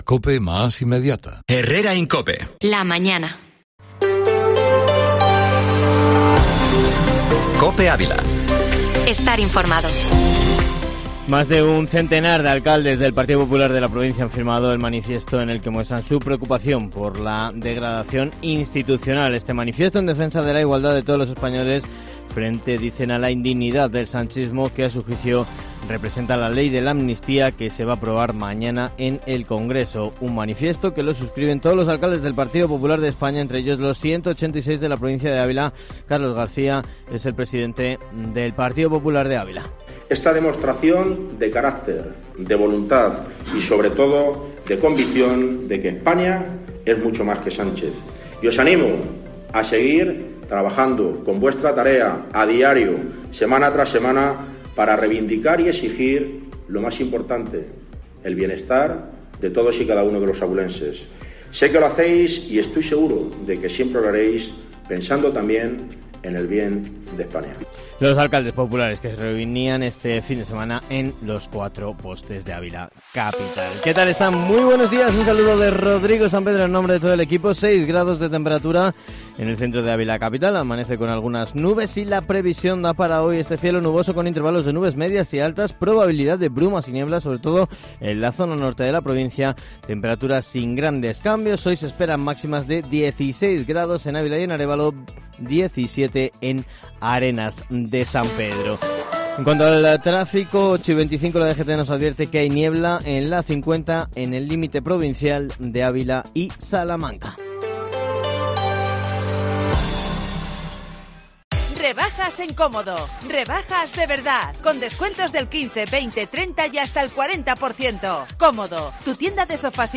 Informativo Matinal Herrera en COPE Ávila